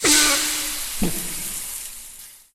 burn.ogg